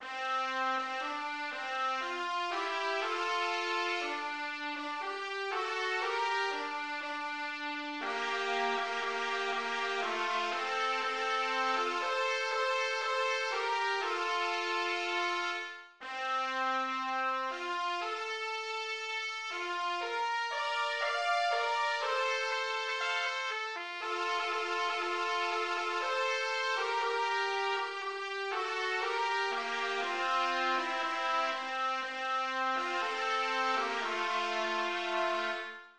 Melody